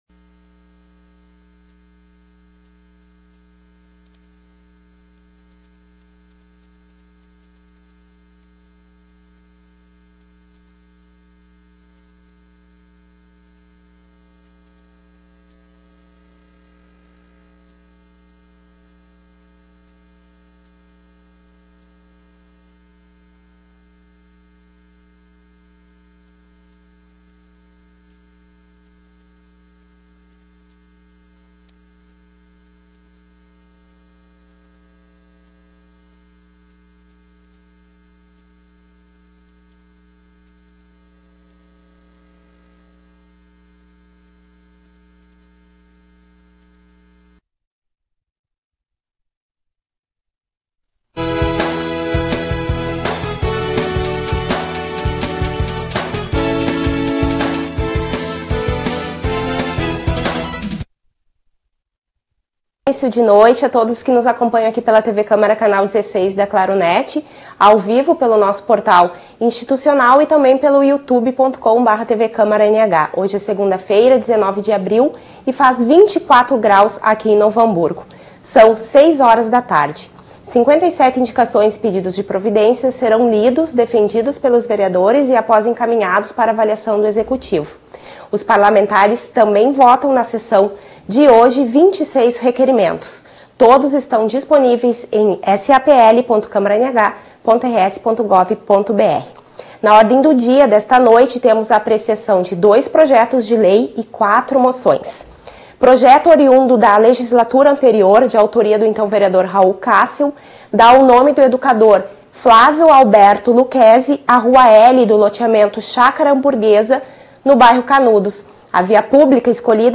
Tipo de Sessão: Sessão Ordinária